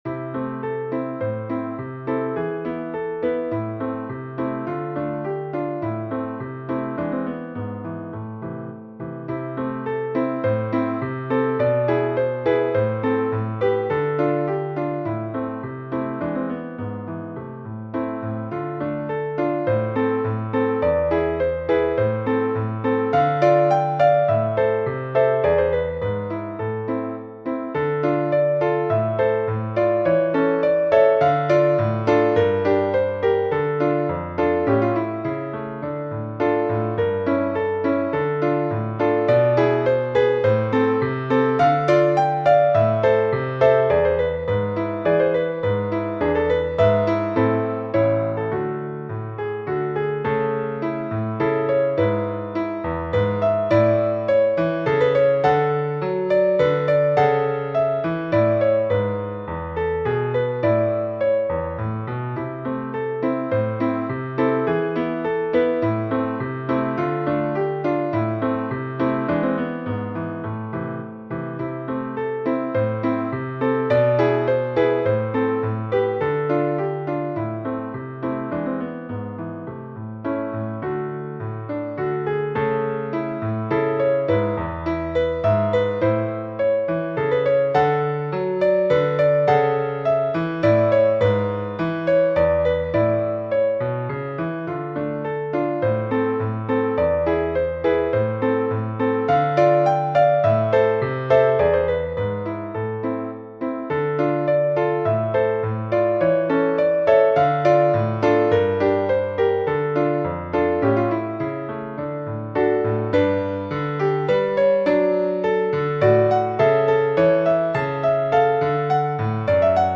Classical Period, Folk and World, Modern Classical, Wedding